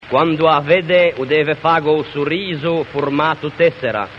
In un diverso incontro vocalico quale quello tra “u” finale con “a” od “e” iniziali, si possono “realizzare” i dittonghi /wa:/ e /we:/ (4), già inclusi nella tabella dedicata ai “dittonghi ascendenti” che si trova nell'articolo sopra menzionato.